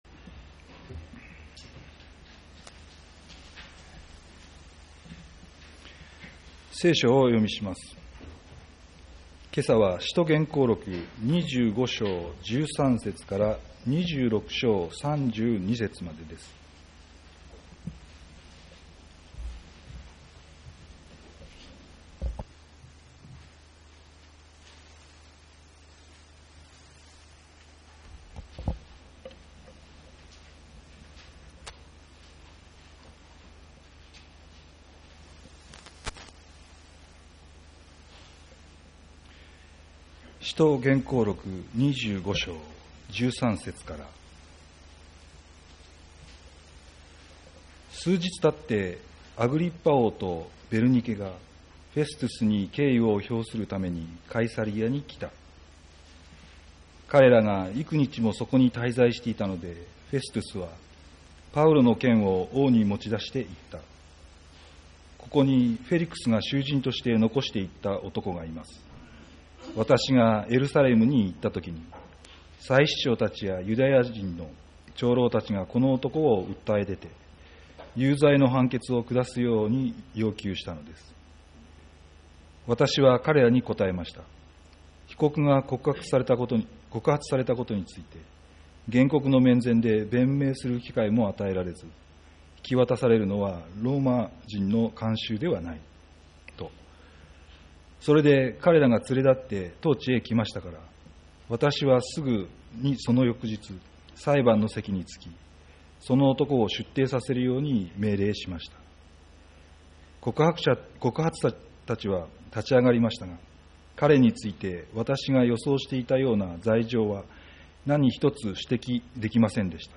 アグリッパ王 2020年11月第1主日礼拝